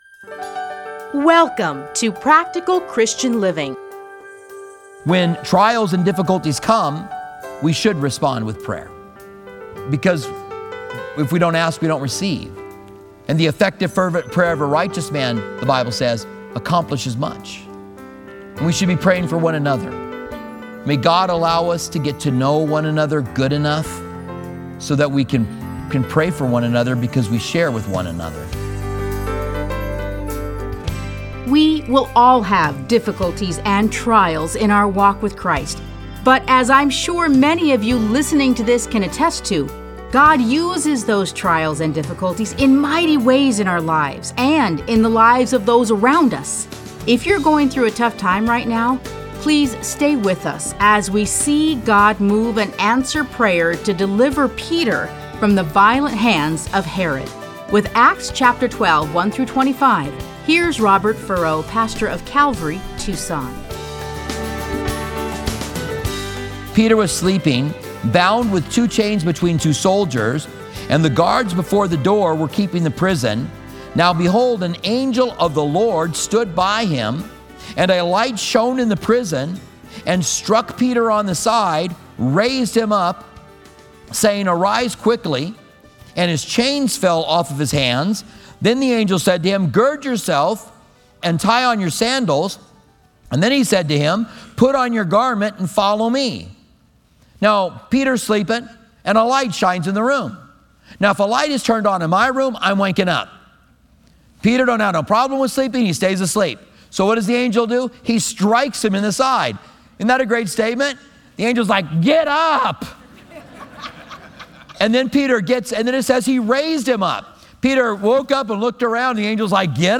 Listen to a teaching from Acts 12:1-24.